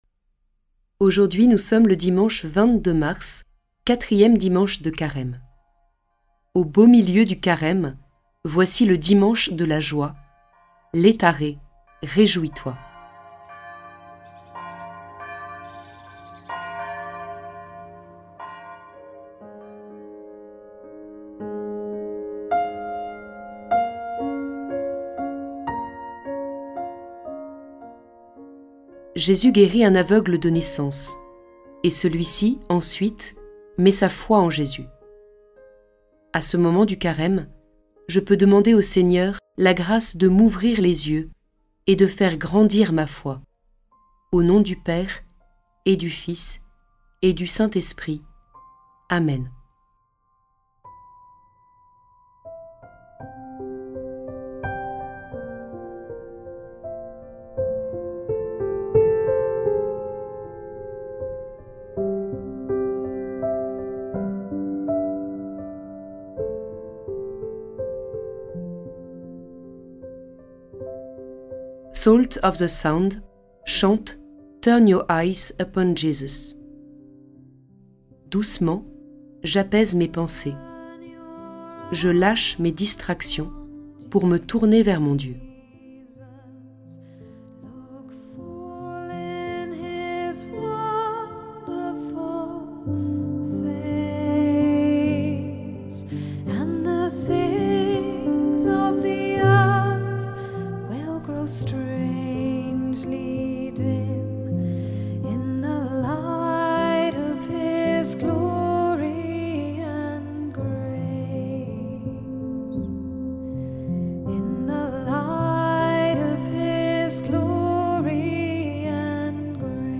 Écouter la méditation avec ‘Prie en Chemin‘ ou la suivre à son rythme avec les pistes ci-dessous.